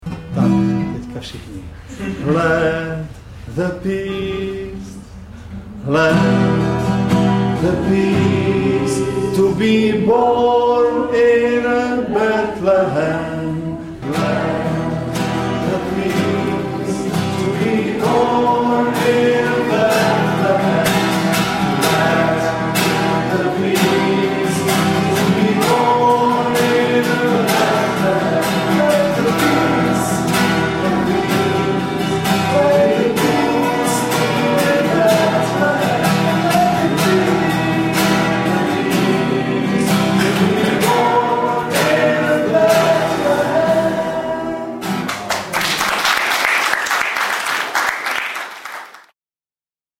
live z koncert Betlem 1998